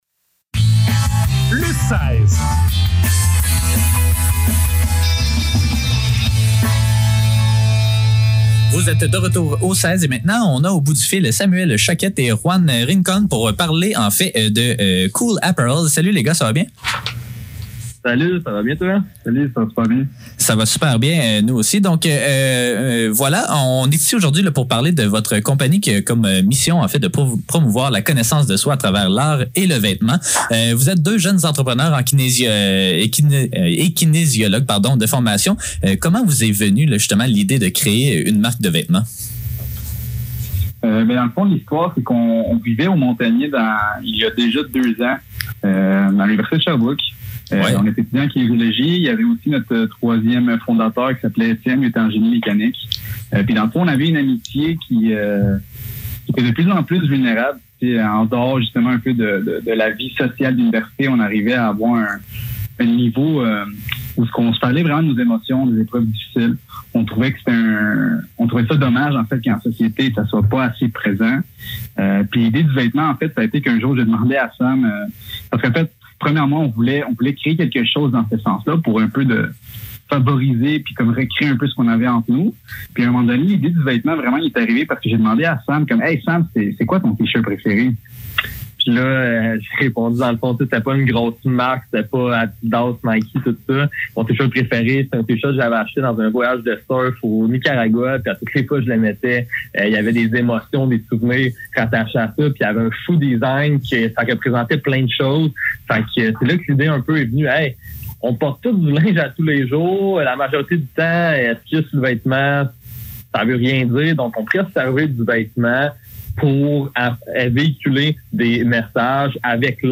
Le seize - Entrevue avec Kool Apparel - 4 octobre 2021